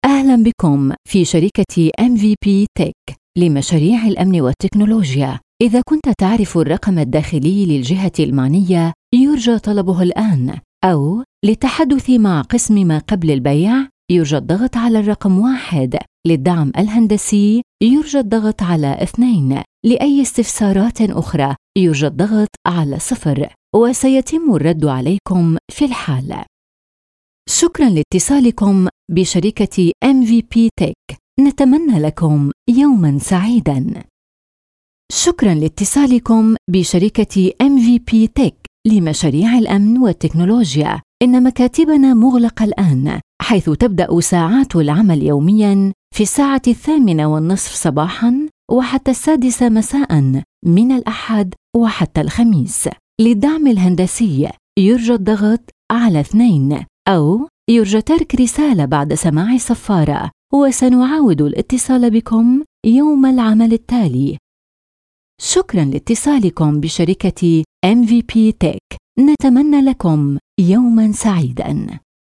Sprechprobe: Industrie (Muttersprache):
If you're business requires an arabic female voice talent for recordings on a one time only or regular frequency, feel free to contact me.